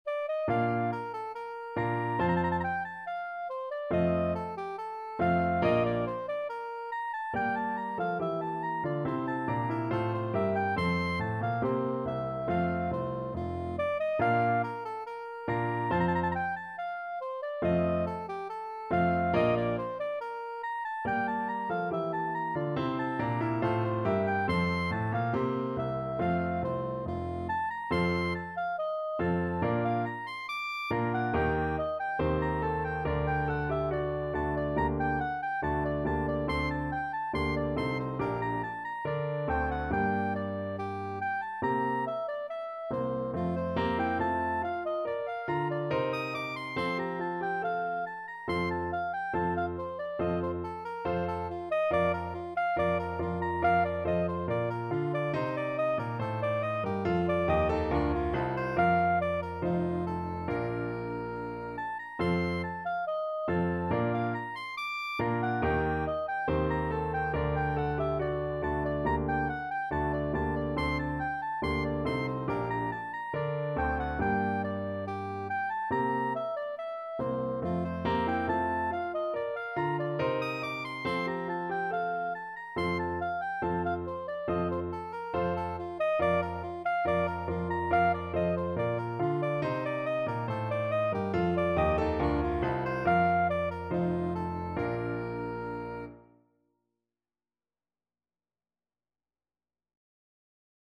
4/4 (View more 4/4 Music)
Classical (View more Classical Soprano Saxophone Music)